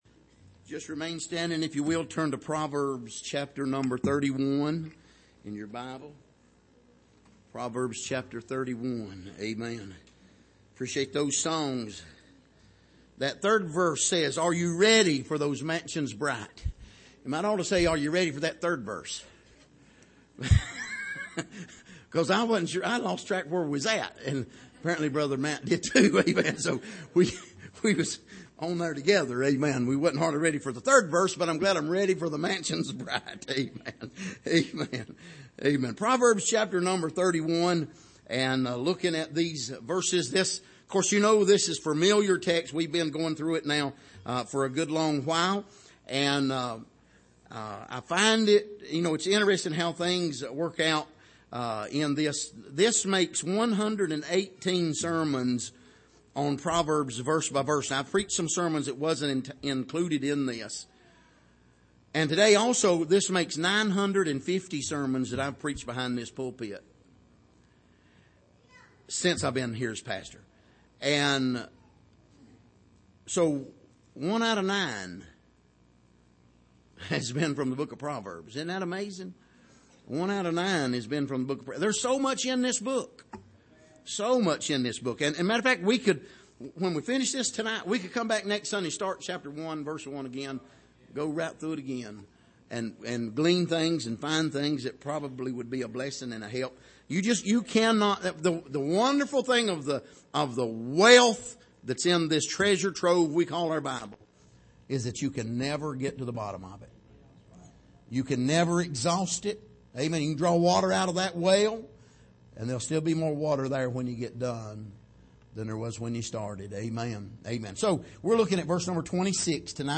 Passage: Proverbs 31:26-31 Service: Sunday Evening